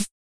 Index of /90_sSampleCDs/300 Drum Machines/Hammon Auto-Vari 64/Hammond Auto-Vari 64 Ableton Project/Samples/Recorded